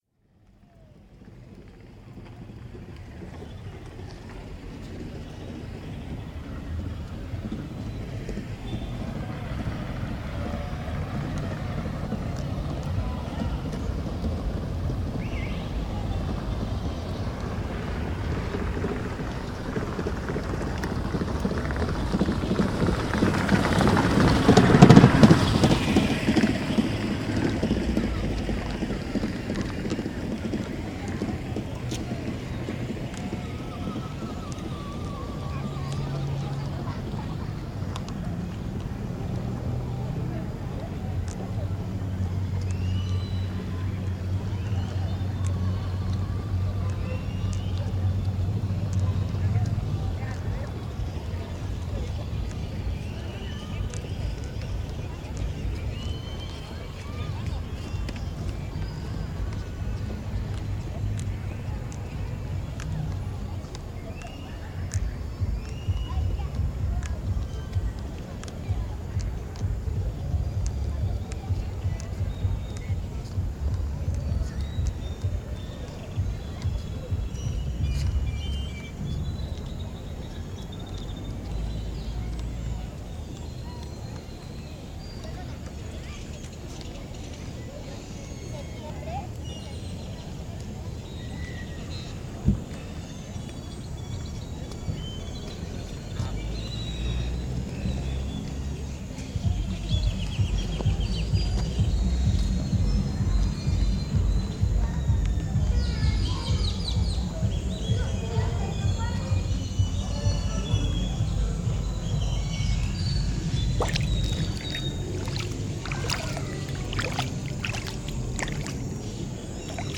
Recorrido Sonoro en Chiapa de Corzo, Celebrando el Día Mundial de la Escucha 2014
El pasado 18 de julio festejamos el Día Mundial de la Escucha realizando un recorrido sonoro en el Parque de Chiapa de Corzo, iniciamos en una zona donde rentan carros electricos para los niños pasamos por La Pila y terminamos en la Pochota.